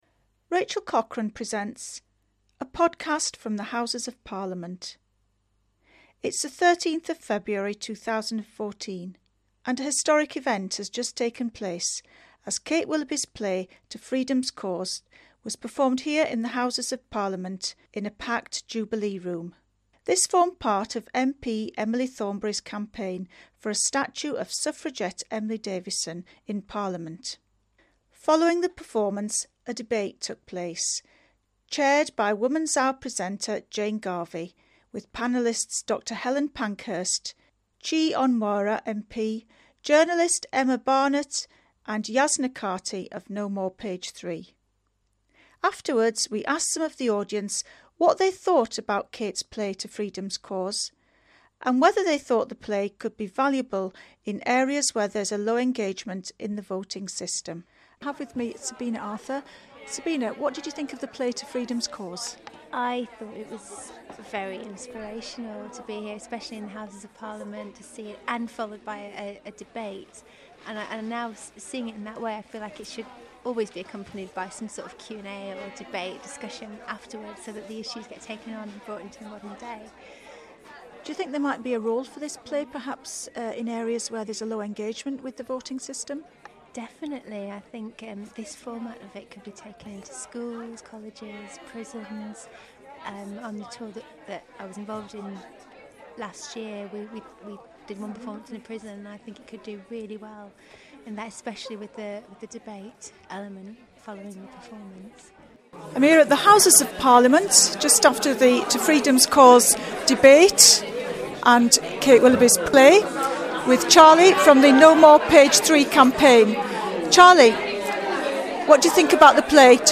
This was followed by a debate chaired by Woman's Hour Presenter Jane Garvey.
In this vox pop, we hear from the audience & those involved in the production.